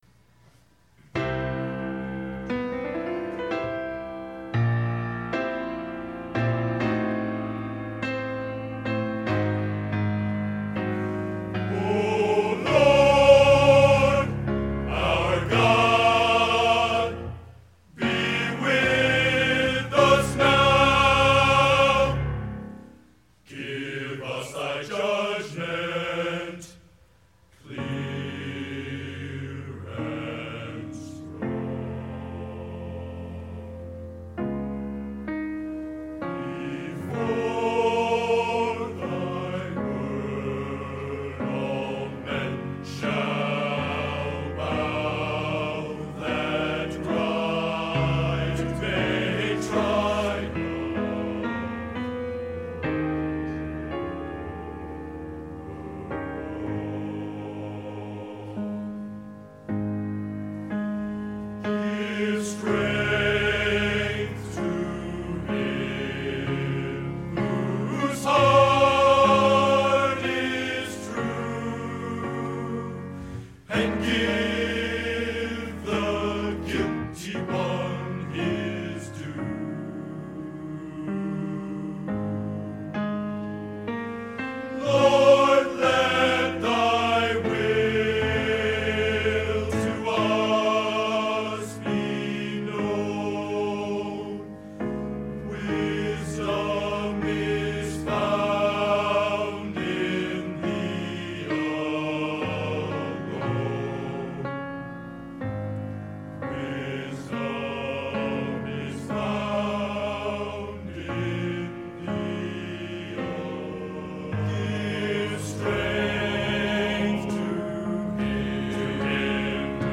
Genre: Opera Sacred | Type: